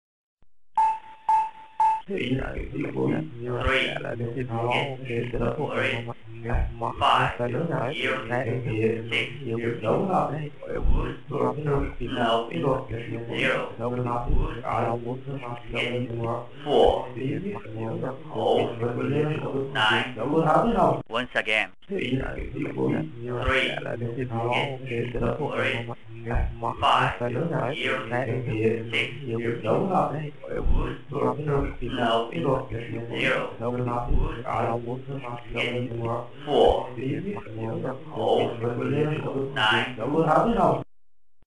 Речь Сиреноголового